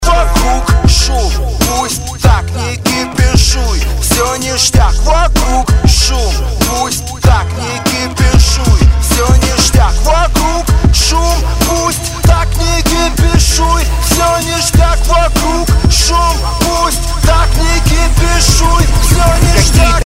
Рэп, Хип-Хоп, R'n'B